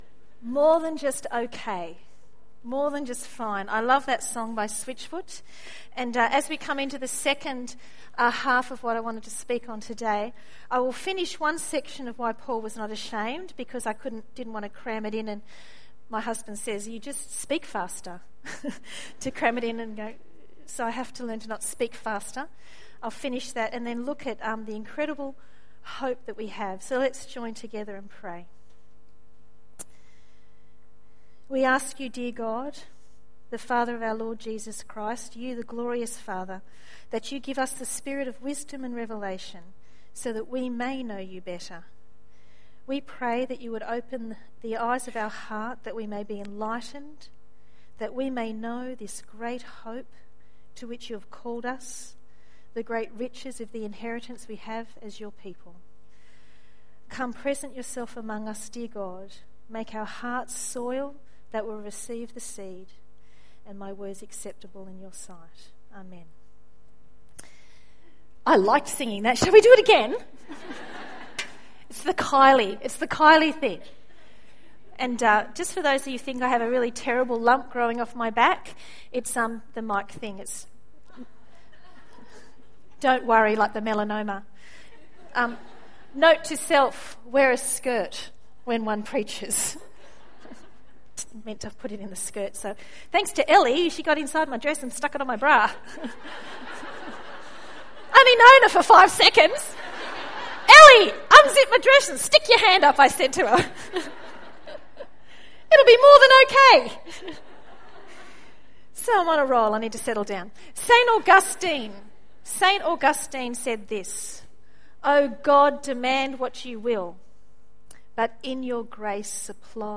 Guest Speaker
recorded live at the Women of Purpose Conference 2012